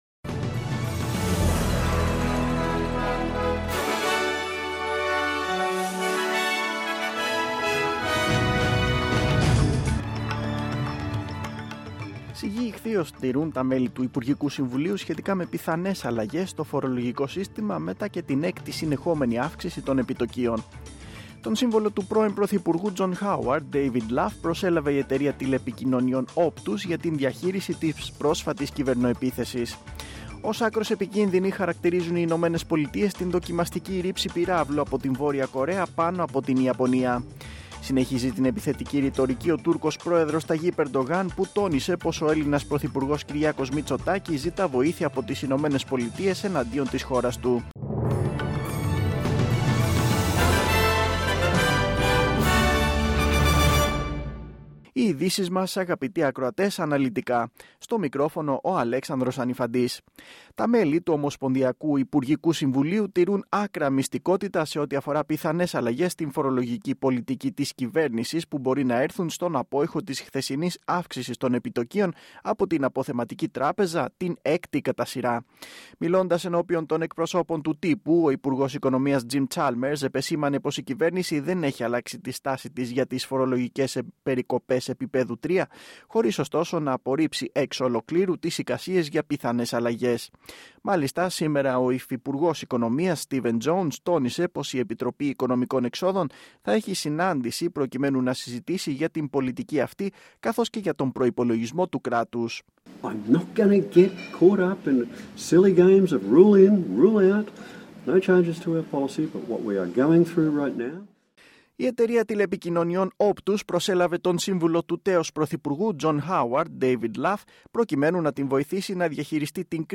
News in Greek.